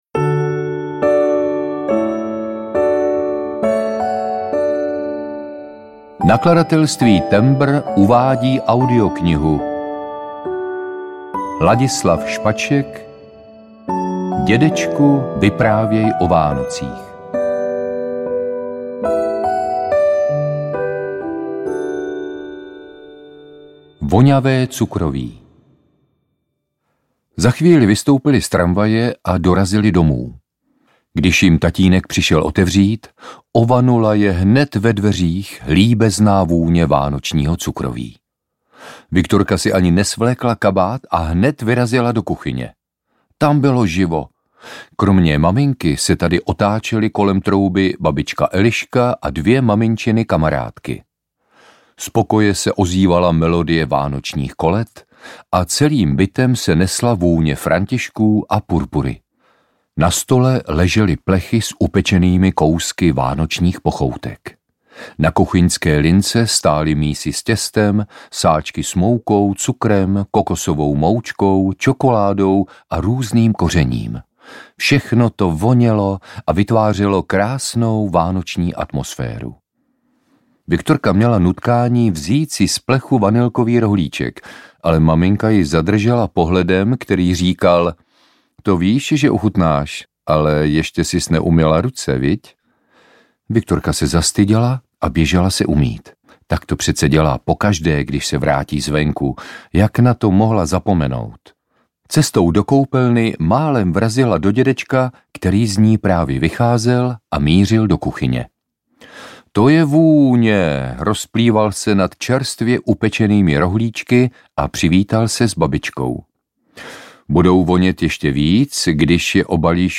Dědečku, vyprávěj o Vánocích audiokniha
Ukázka z knihy
• InterpretLadislav Špaček